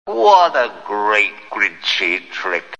Computer Sounds